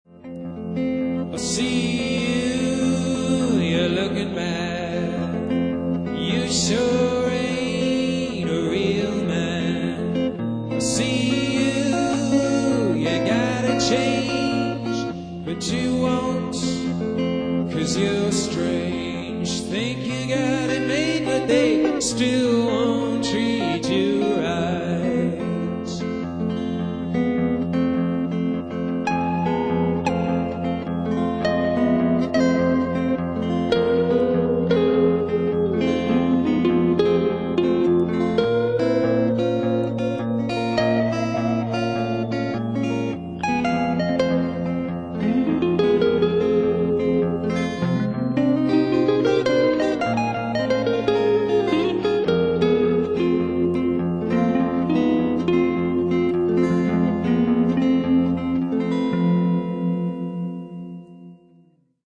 “Strange Blues” was written and recorded around 2000 and its just a weird take on the traditional blues form with lyrics describing my ‘strange’ feelings at the time!